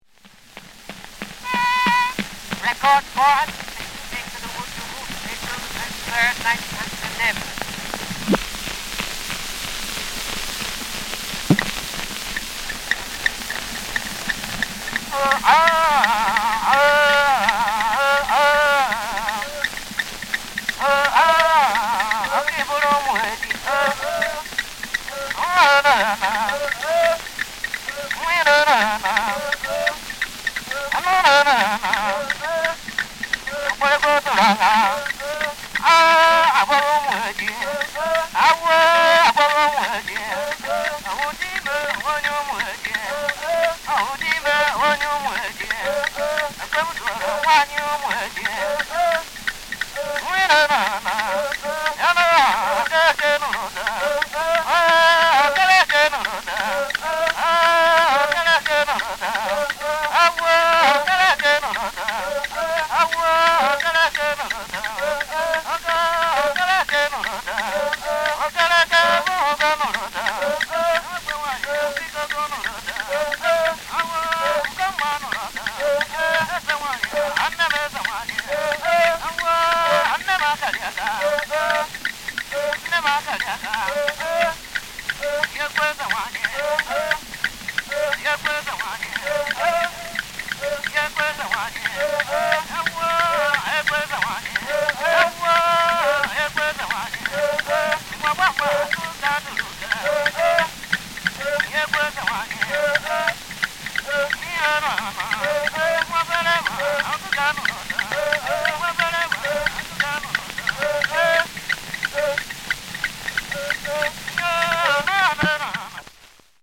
Igbo vocal group with leader and percussion